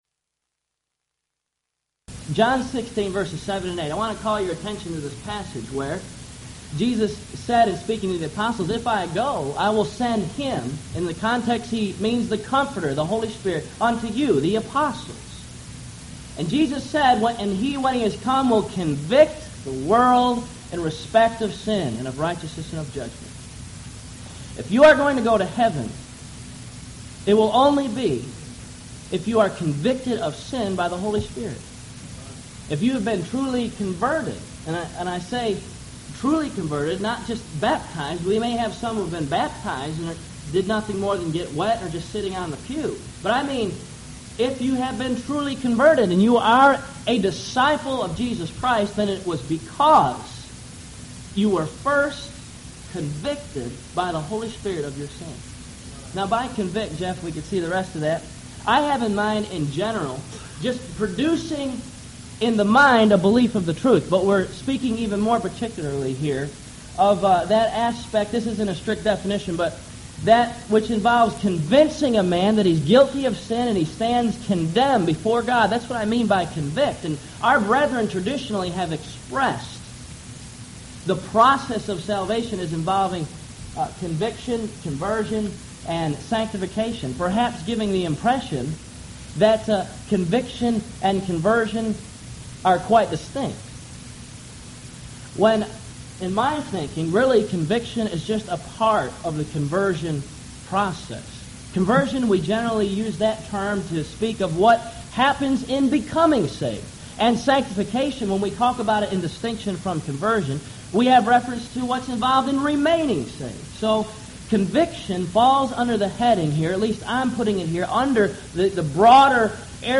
Event: 1998 Houston College of the Bible Lectures
If you would like to order audio or video copies of this lecture, please contact our office and reference asset: 1998Houston13